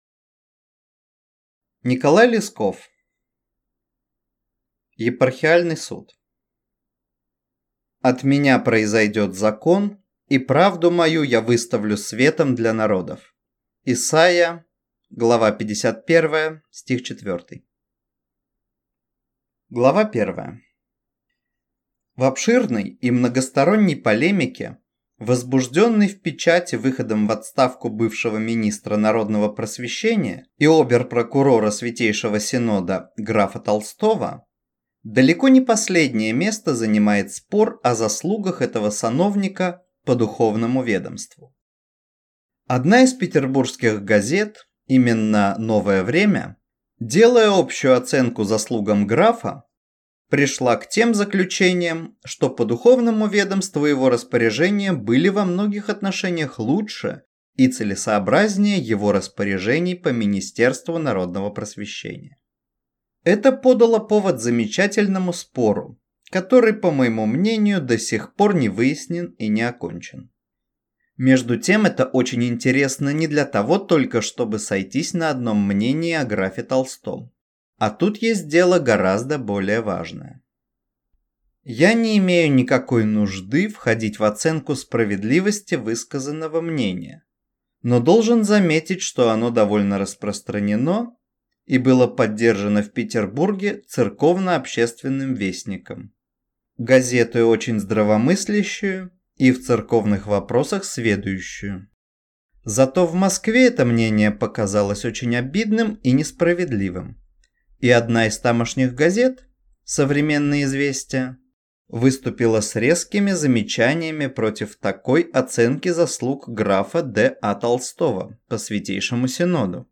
Аудиокнига Епархиальный суд | Библиотека аудиокниг